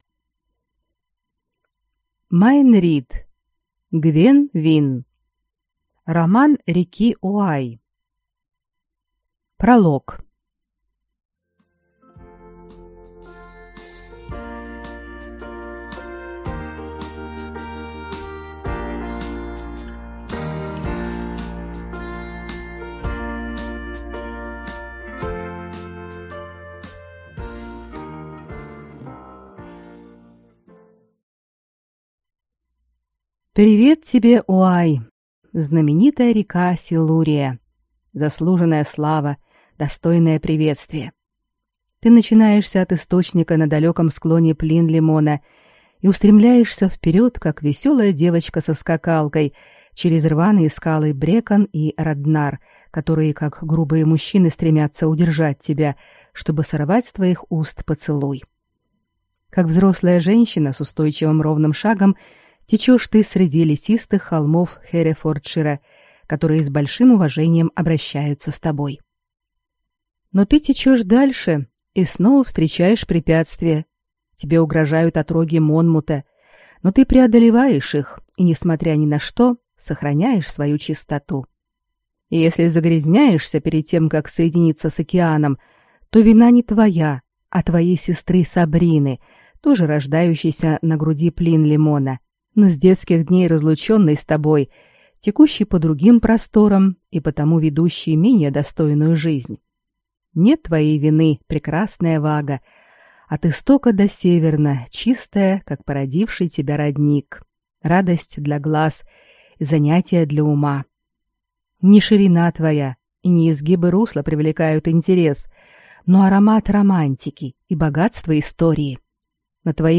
Аудиокнига Гвен Винн | Библиотека аудиокниг
Прослушать и бесплатно скачать фрагмент аудиокниги